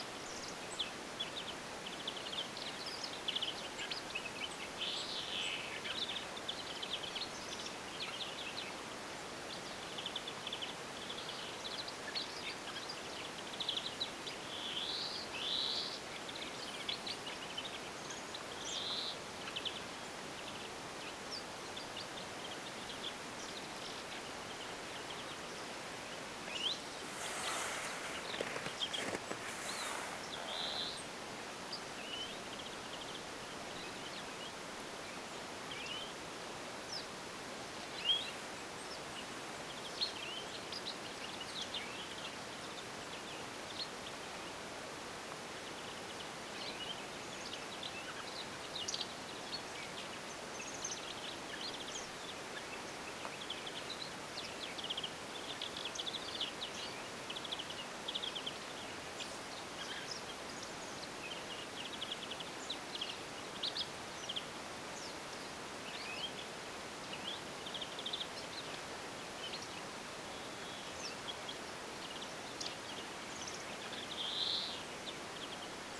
Tänään lauloi siellä jatkuvasti viherpeippo.
tuollaista viherpeipon laulua
tuollaista_viherpeippolinnun_laulua_saaren_linturuokinnalla.mp3